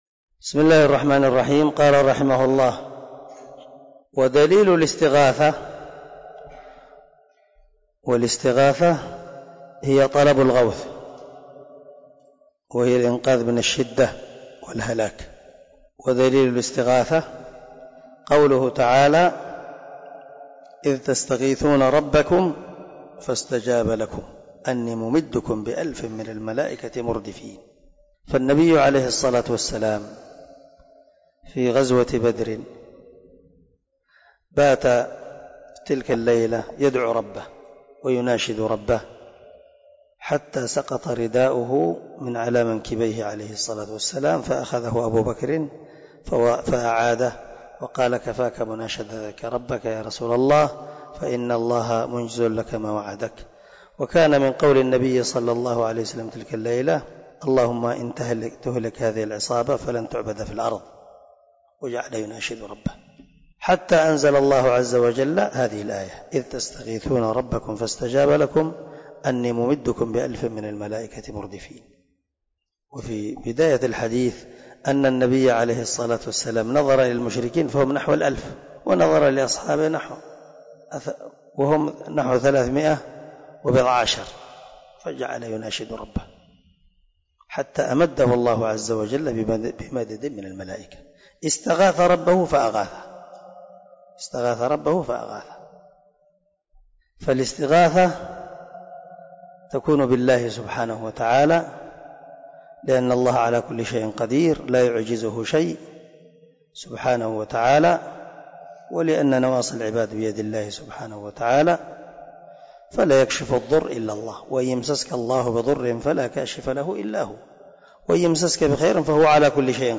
🔊 الدرس 19 من شرح الأصول الثلاثة
الدرس-19-ودليل-الاستغاثة.mp3